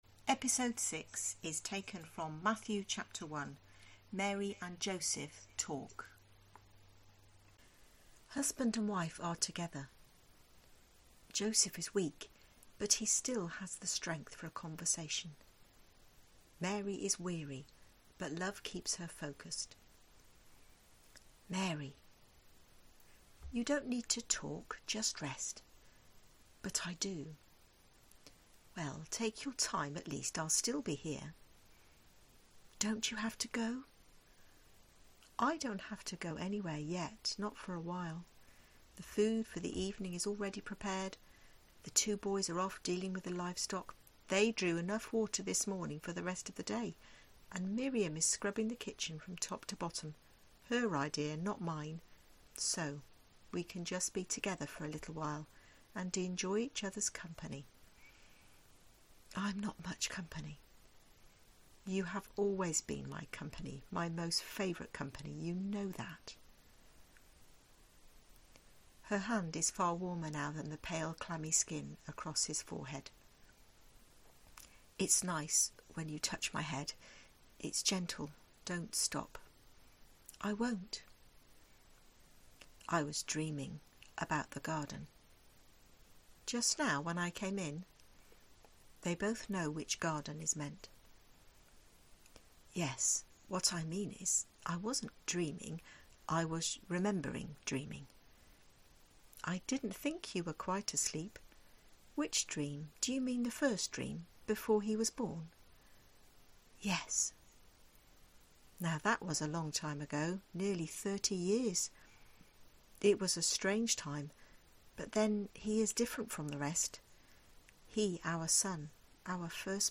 So here is something you can listen to instead: twelve voices from the Nativity story based on the first two chapters of Matthew and Luke, including… singing and silence, maps and mystery, plans and plots, flights and frights, a birth and a death, as well as angels, dreams and prophecies. Written for grown-ups rather than children, each audible episode – about 20 minutes long – is either a reflection on or a narration of, the most important story of all.